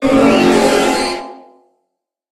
Cri de Méga-Staross dans Pokémon HOME.
Cri_0121_Méga_HOME.ogg